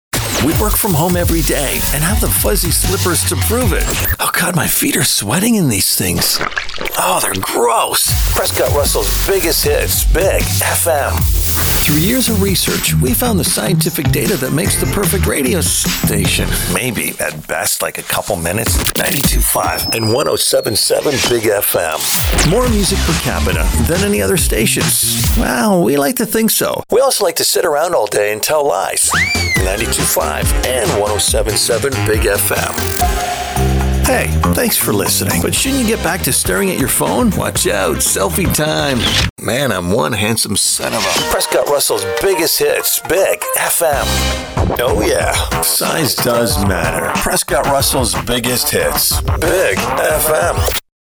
His voice—rich, textured, and instantly recognizable—sets him apart from the crowd in a competitive industry.
Demo_ROCK_AC